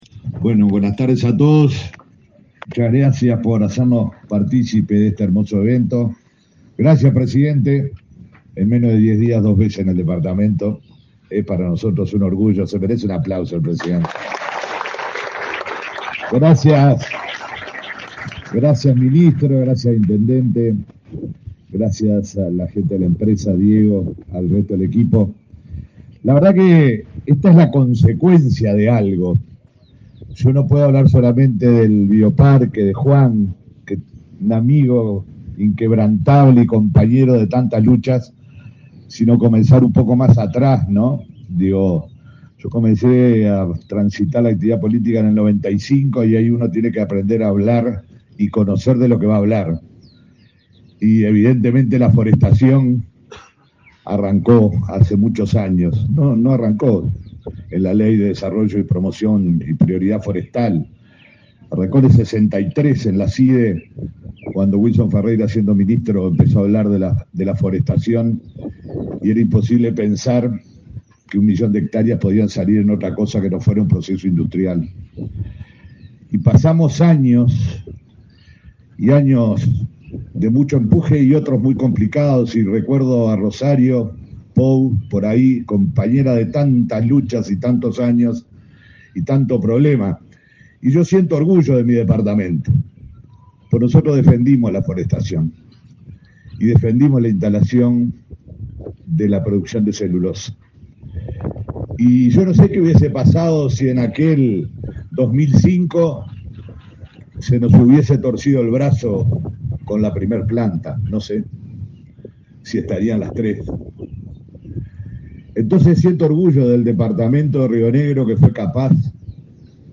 Palabras del intendente de Río Negro, Omar Lafluf
El presidente de la República, Luis Lacalle Pou, participó este 25 de octubre en la inauguración del Centro de Visitantes del Bioparque M' Bopicuá de